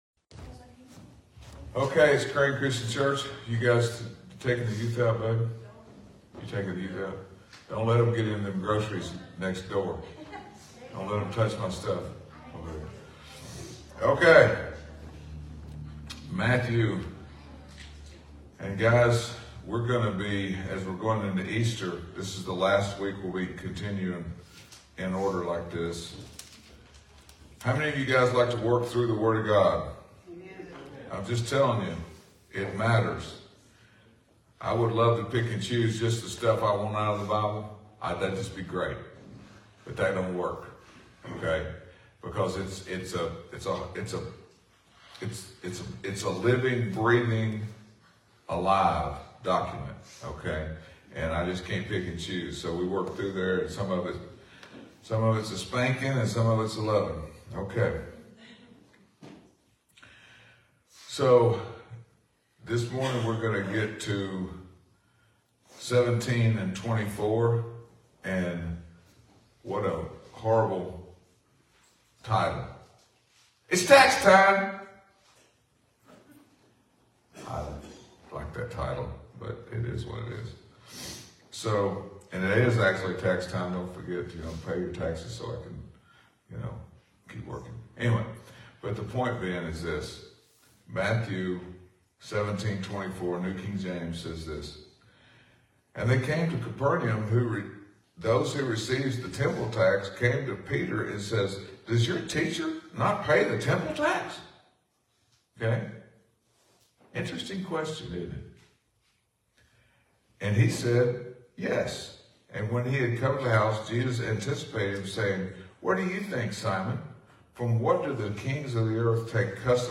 Sunday Sermon 3-17-24